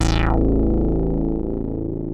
OSCAR 8 A1.wav